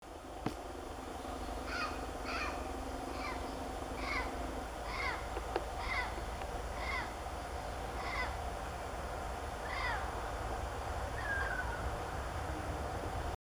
Calancate Cara Roja (Psittacara mitratus)
Nombre en inglés: Mitred Parakeet
Fase de la vida: Adulto
Localidad o área protegida: Parque Nacional Calilegua
Condición: Silvestre
Certeza: Vocalización Grabada